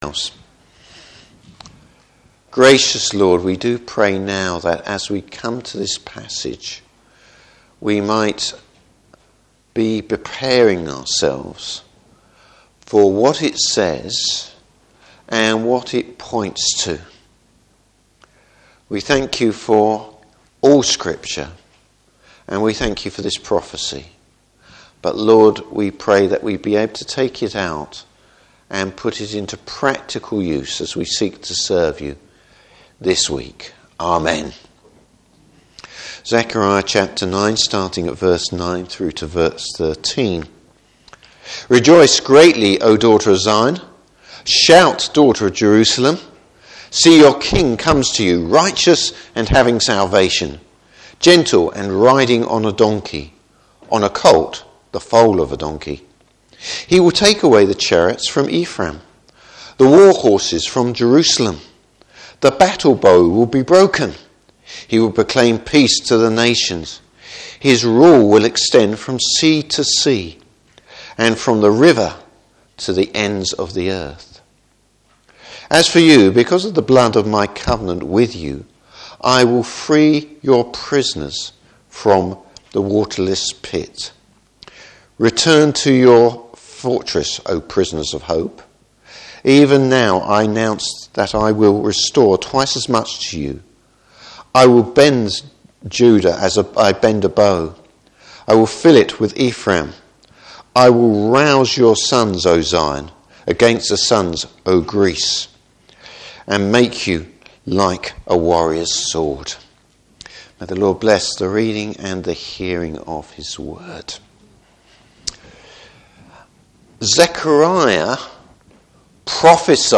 Service Type: Morning Service The prophecy concerning Jesus’ entry into Jerusalem.